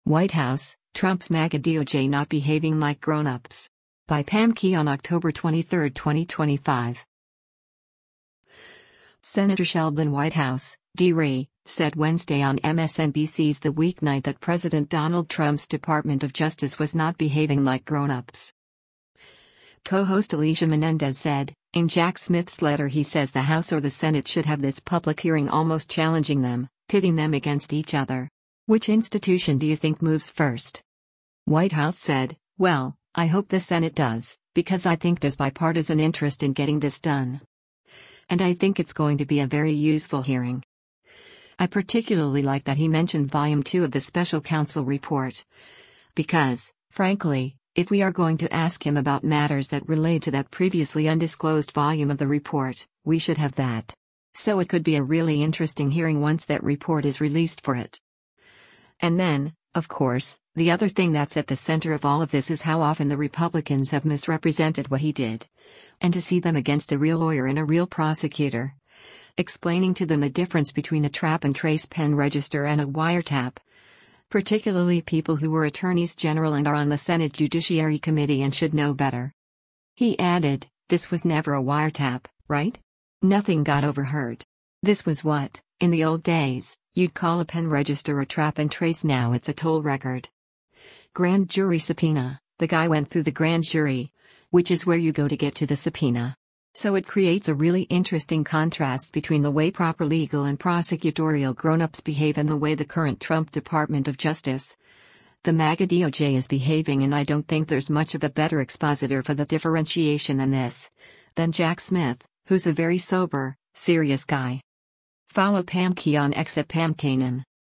Senator Sheldon Whitehouse (D-RI) said Wednesday on MSNBC’s “The Weeknight” that President Donald Trump’s Department of Justice was not behaving like “grown-ups.”